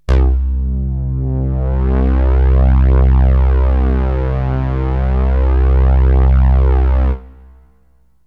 SYNTH BASS-1 0003.wav